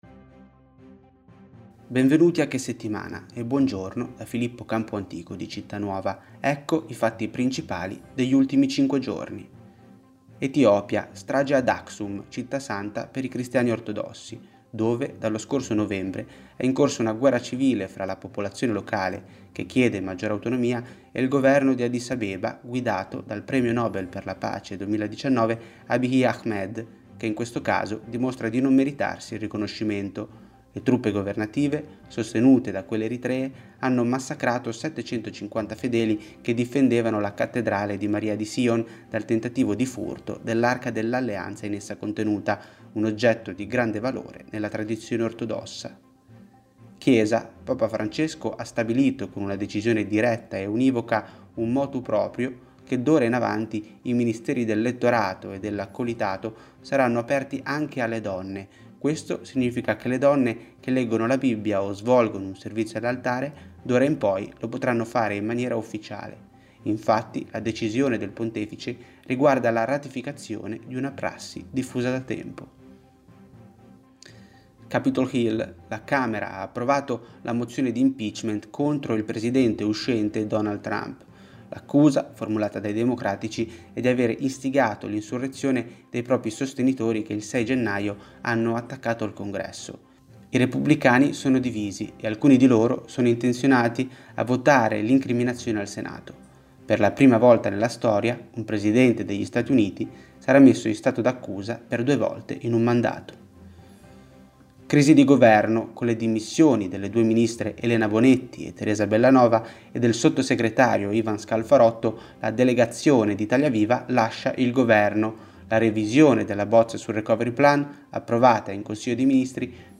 Italia > Audioletture